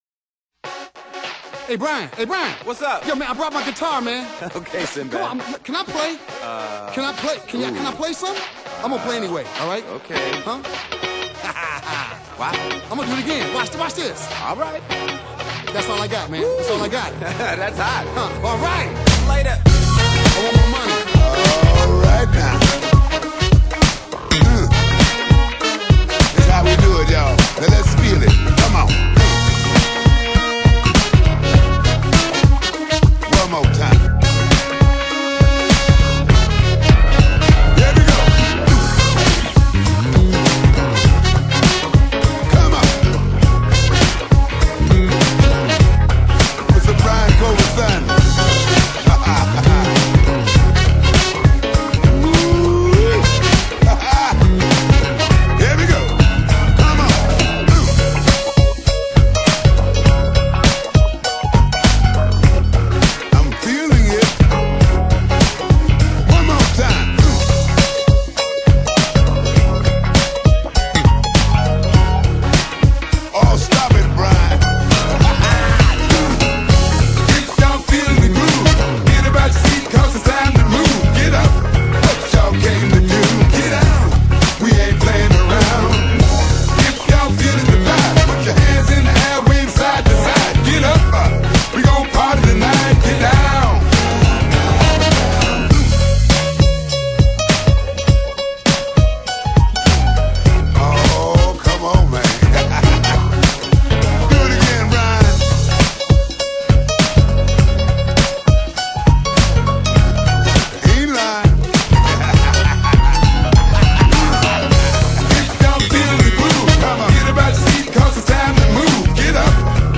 an all-out Go-Go party jam